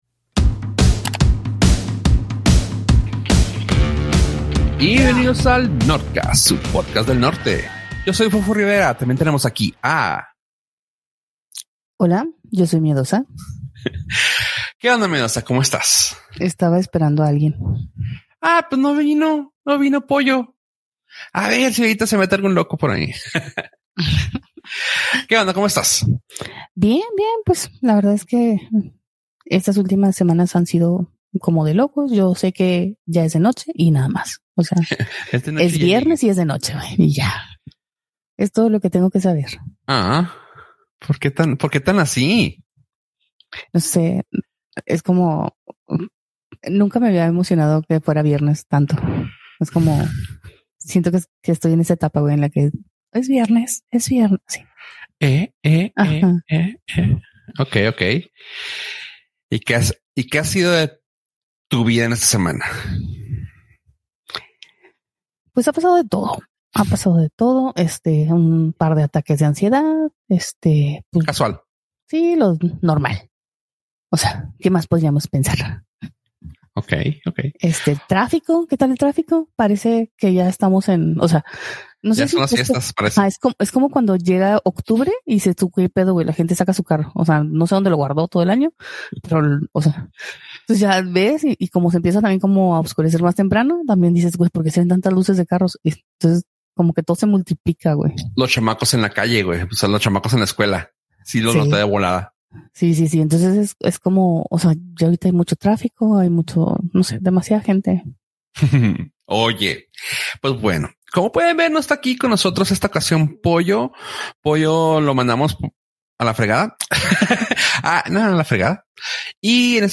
Nortcast Nortcast Un podcast de entretenimiento, tecnología y cultura pop. Presentado desde el Norte (Ciudad Juárez, Chihuahua).